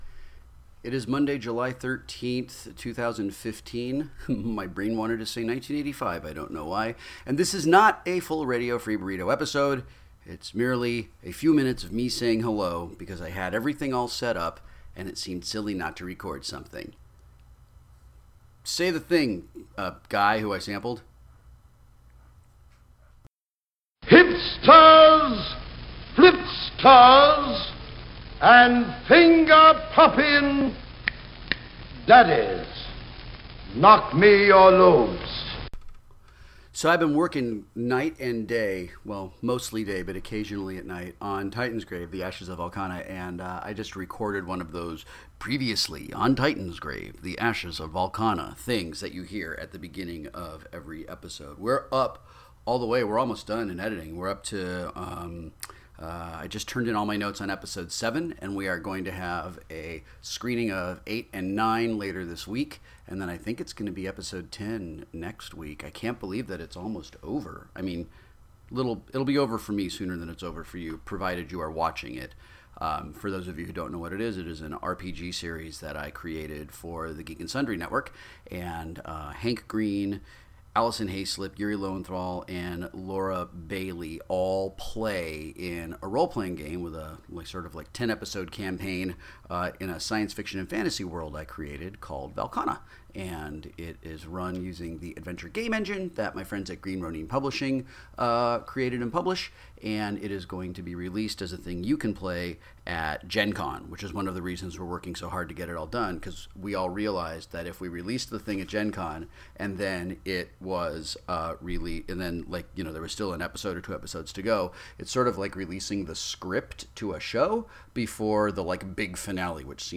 I have some time today, and I have all my gear set up, so I talked at you for a little bit about some things.